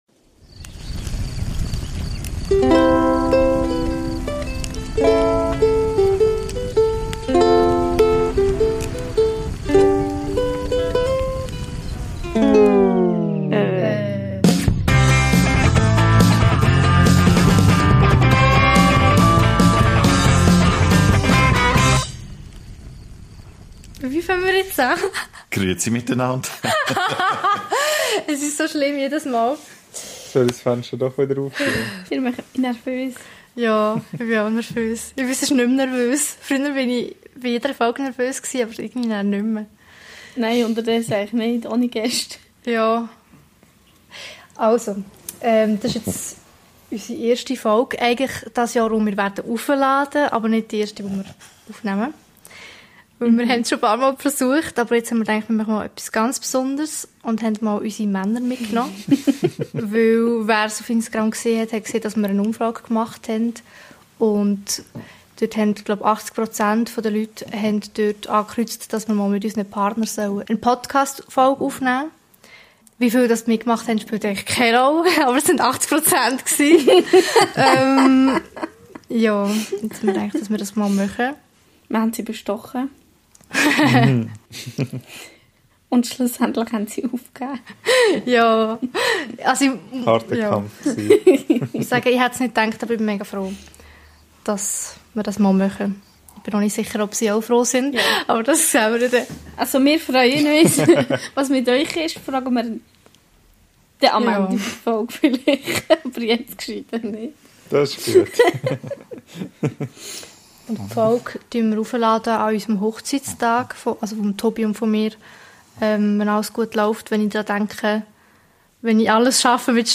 Diesmal wird aus einer etwas anderen Perspektive erzählt. Wie es ihre Partner mit ihnen aushalten und sie im Alltag unterstützen: das Interview.